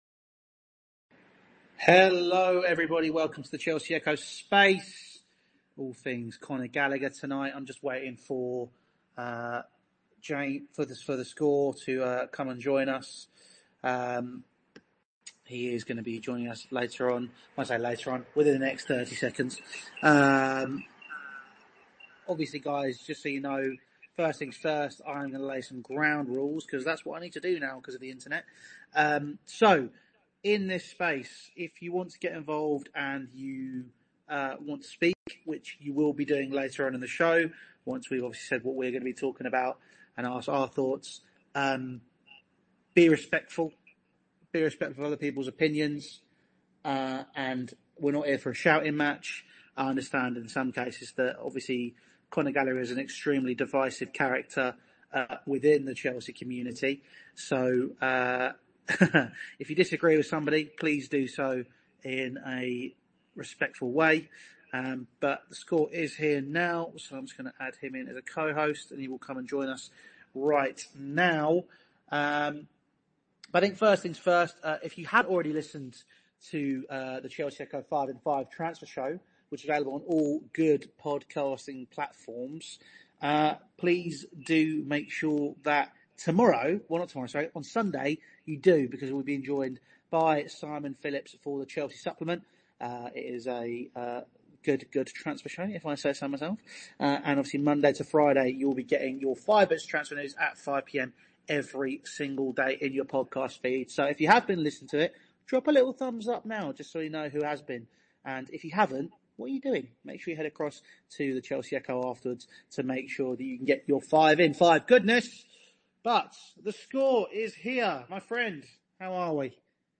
and take your calls to discuss the developing story...